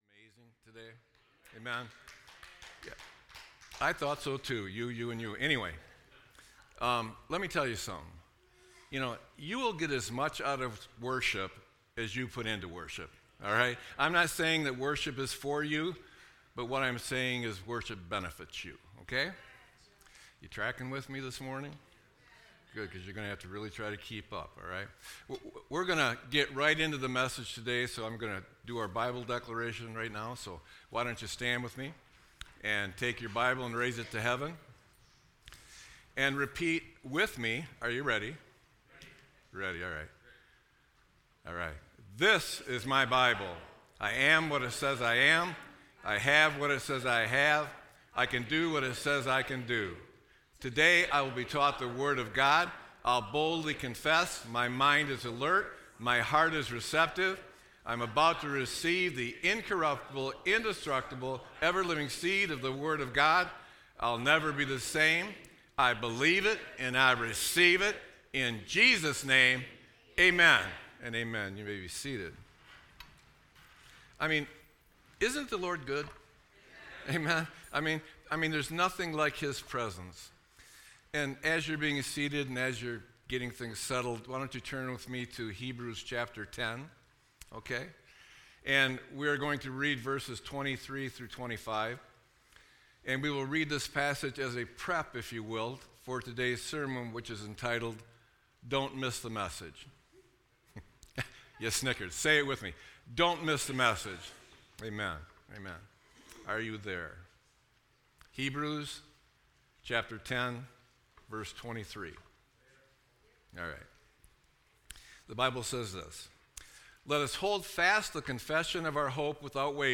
Sermon-8-3-25.mp3